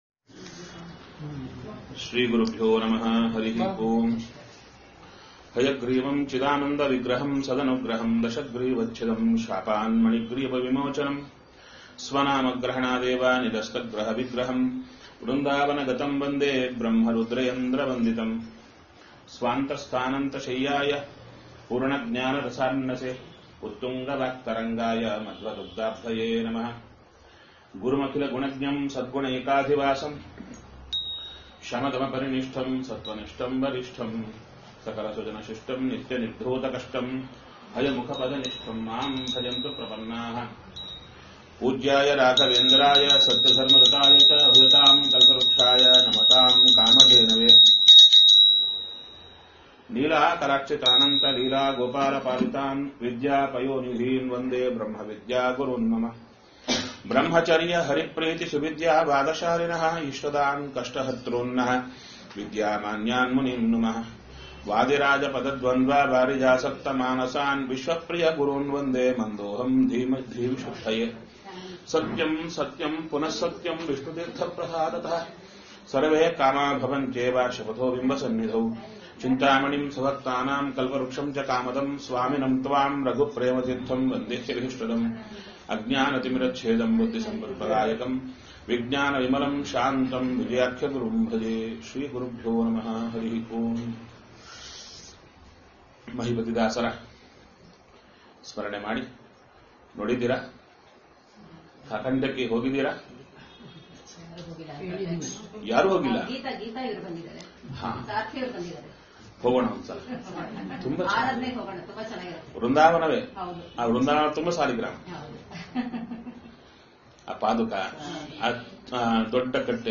Spiritual discourses, audio messages, events, and downloadable resources from Kurnool Achars Chintana.